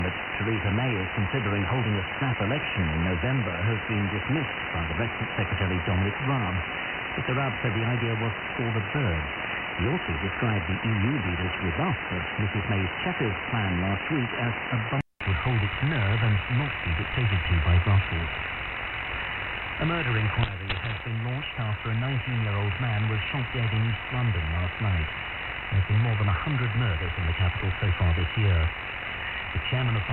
For the comparison I made audio recordings of the two receivers.
Second 0-15 >> SDRplay RSPduo
Second 15-30 >> Winradio G33DDC Excalibur Pro
CW
Timesignal Anthorn GBR